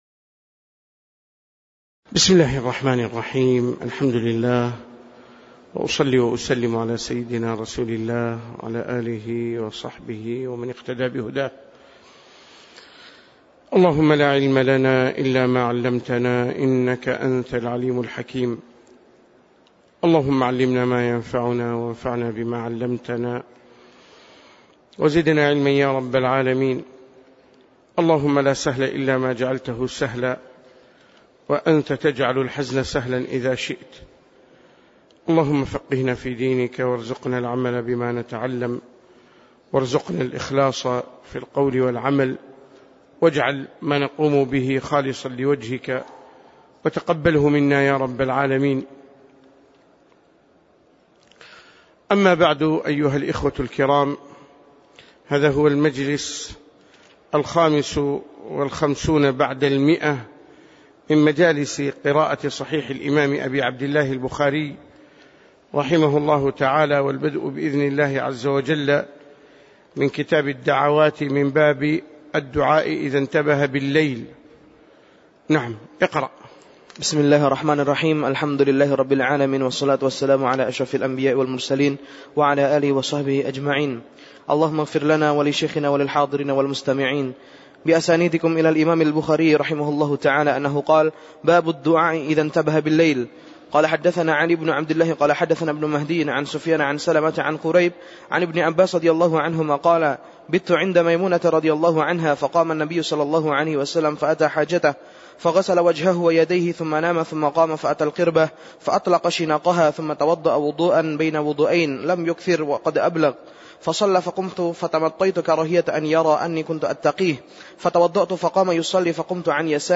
تاريخ النشر ٢٧ محرم ١٤٣٩ هـ المكان: المسجد النبوي الشيخ